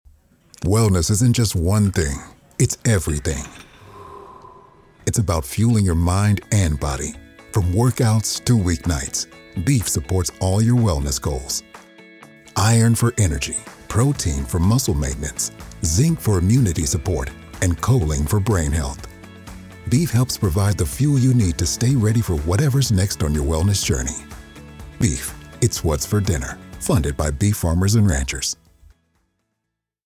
National Radio Ads